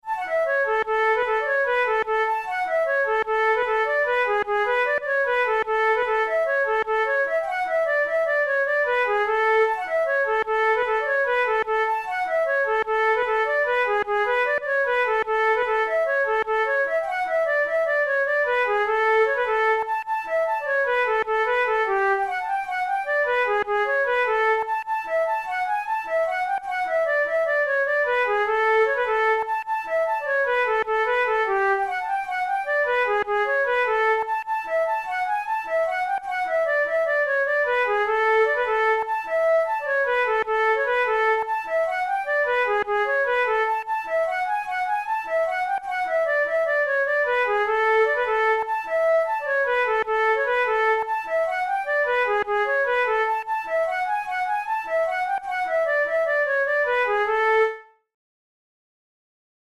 Traditional Irish jig
This lively three-part jig is taken from Chicago Police Captain Francis O'Neill's celebrated collection Music of Ireland, published in 1903.